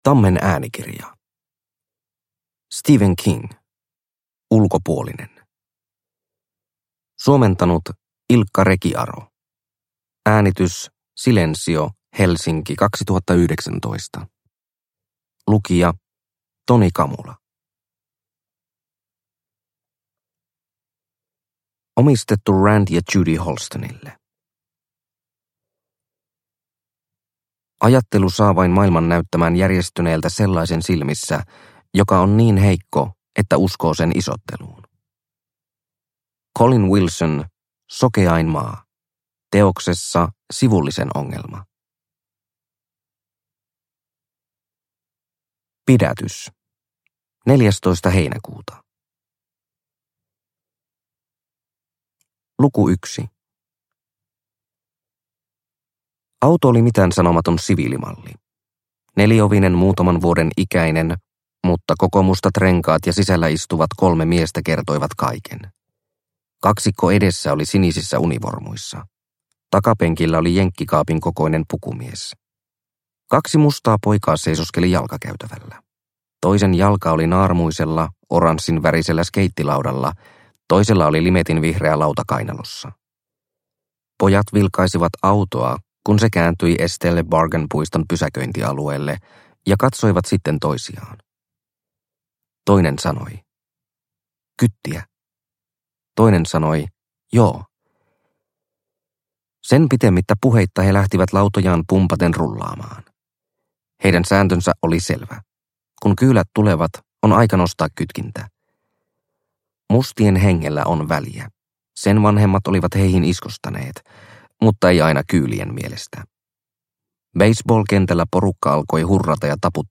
Ulkopuolinen – Ljudbok – Laddas ner